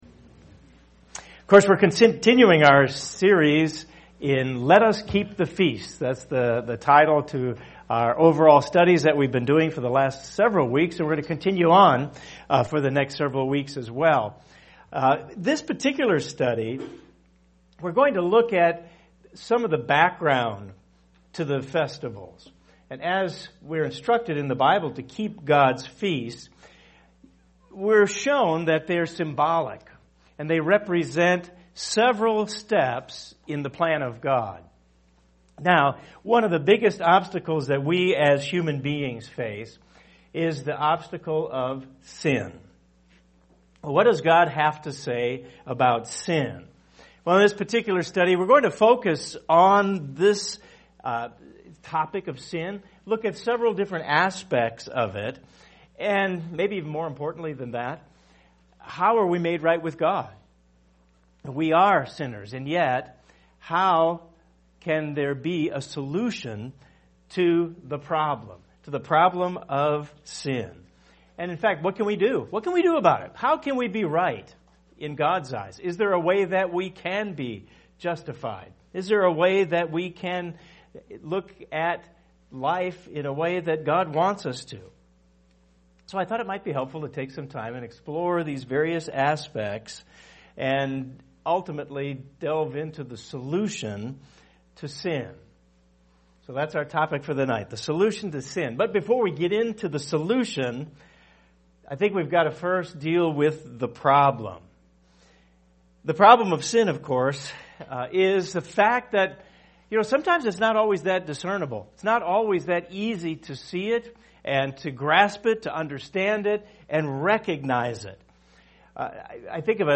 This is part 3 in the Bible study series: Let Us Keep the Feasts.